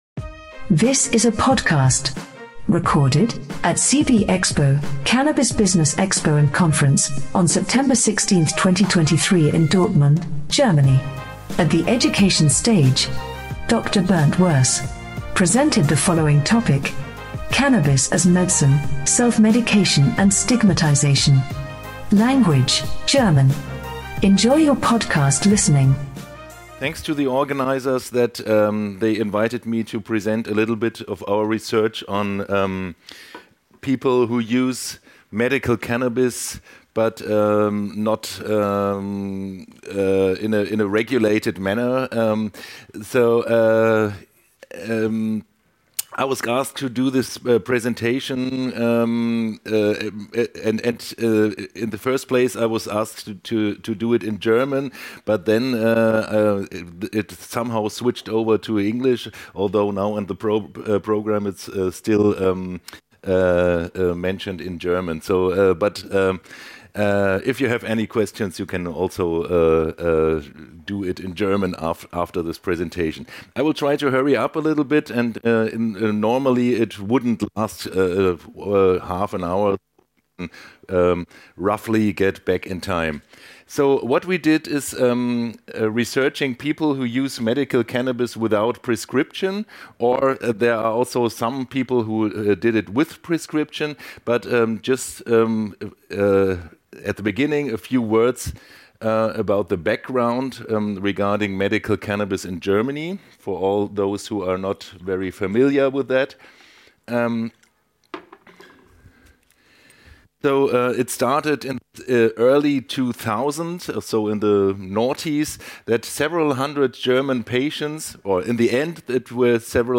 Our expert panel will provide their insights on the future of the German medical market.